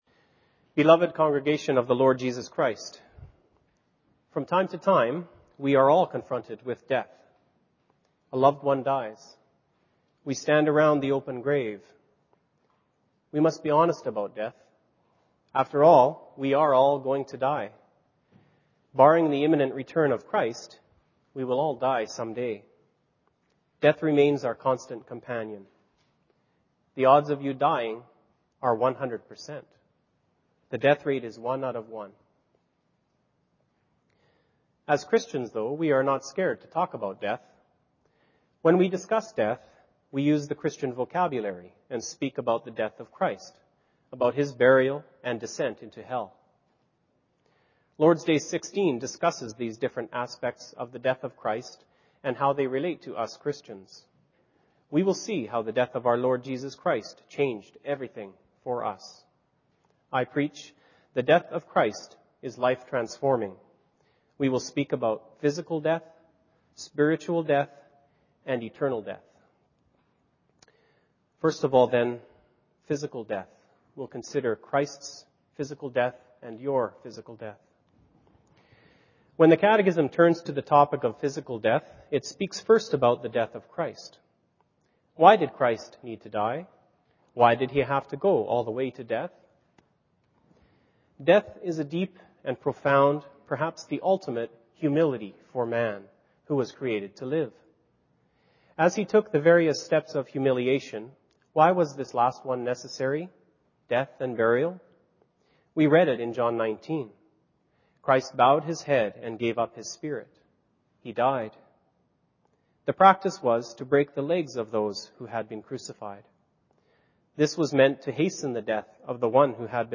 Sermon
Service Type: Sunday Afternoon